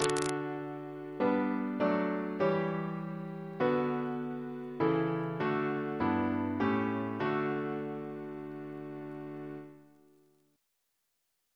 Single chant in D Composer: George Mursell Garrett (1834-1897), Organist of St. John's College, Cambridge Reference psalters: ACB: 200; OCB: 6